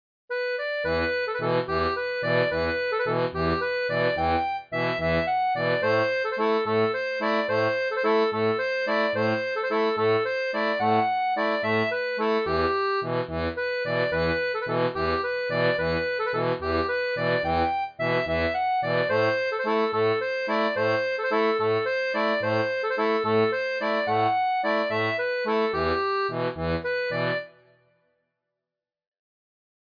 • Une tablature pour diato à 3 rangs
Type d'accordéon
Folk et Traditionnel